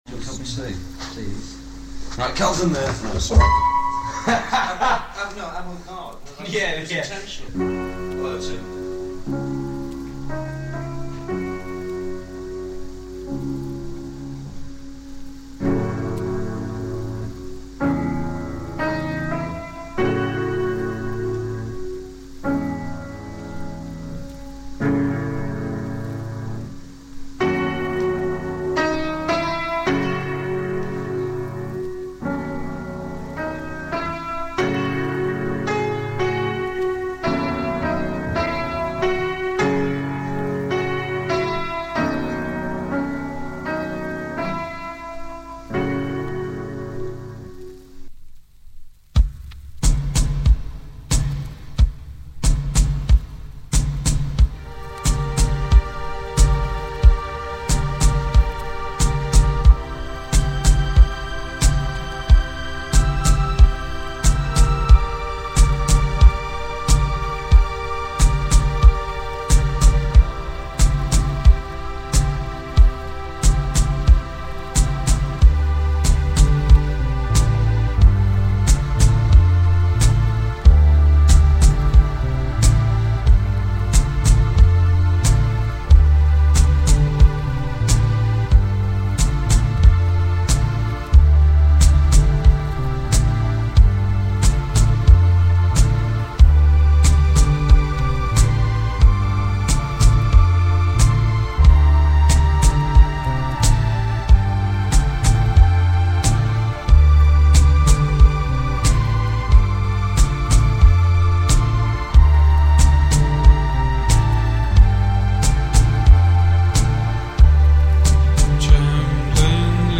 Bodiless, effortless.